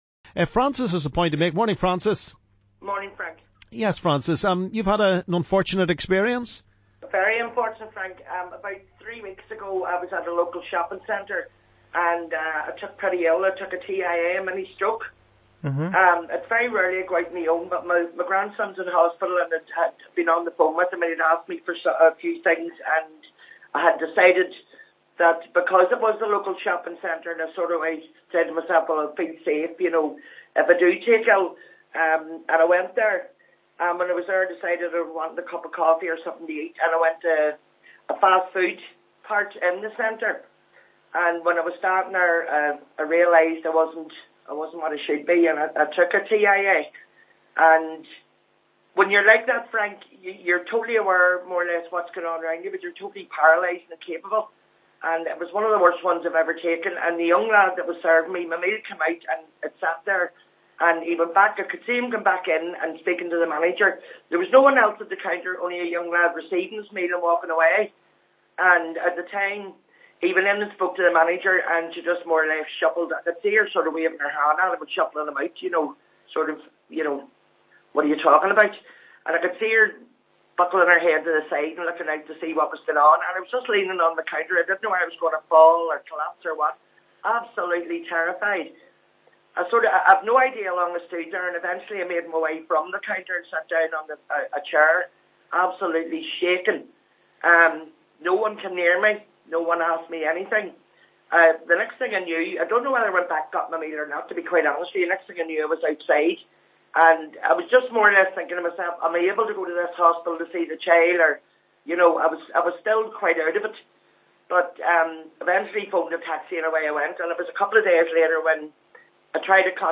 LISTEN ¦ Callers having their say...